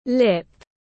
Môi tiếng anh gọi là lip, phiên âm tiếng anh đọc là /lɪp/.
Lip /lɪp/